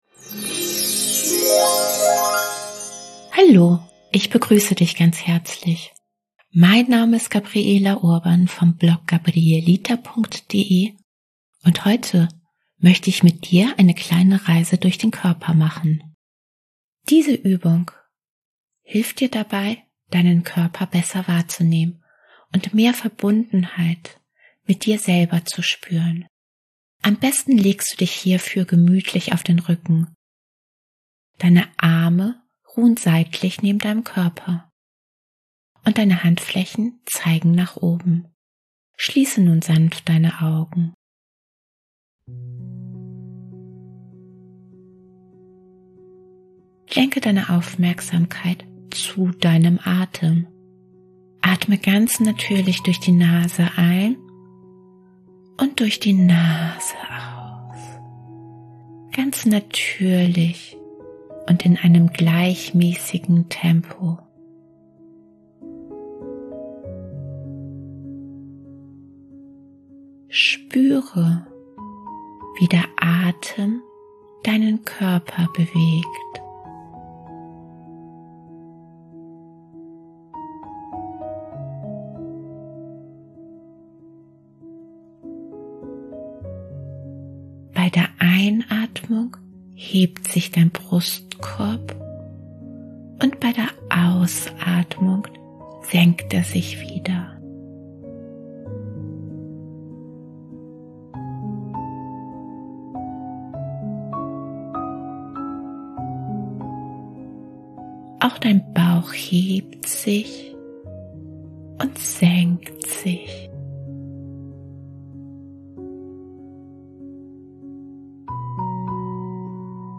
Diese Meditationsübung, die gleichzeitig eine geführte Körperreise ist, ermöglicht dir, deinen Körper besser wahrzunehmen und mehr Verbundenheit zu dir selber zu spüren. Am besten legst du dich hierfür gemütlich auf den Rücken.